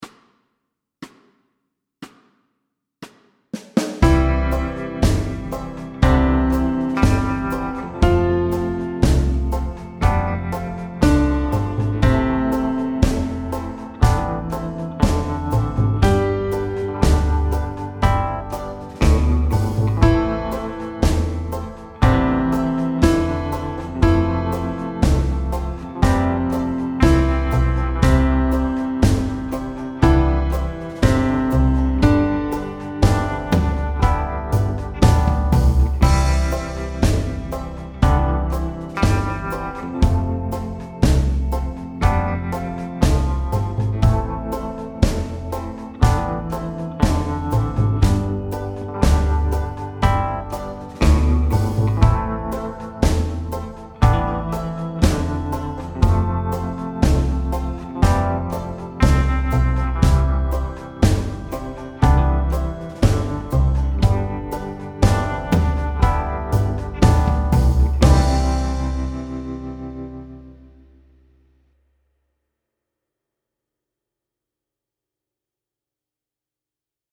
Slow C instr (demo)